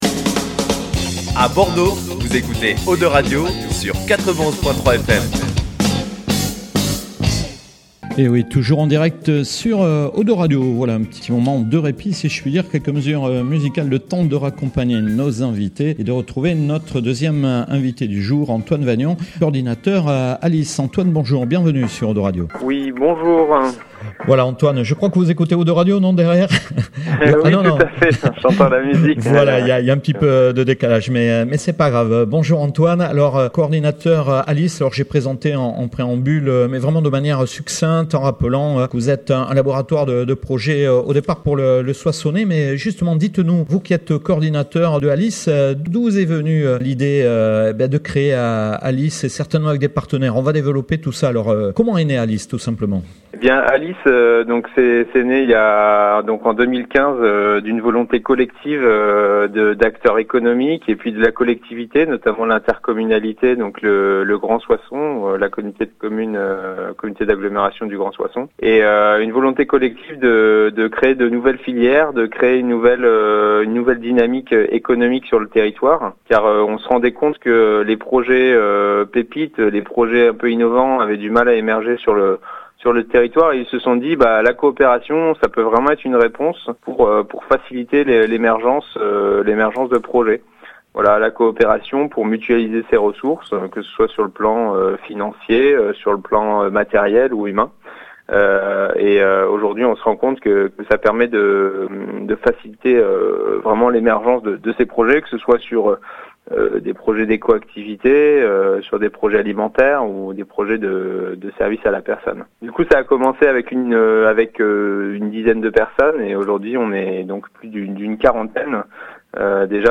30/01/2018 : Interview GNIAC / 02 Radio : présentation du PTCE ALISS